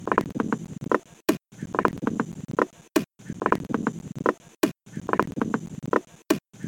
Inciso 1 – en 4/4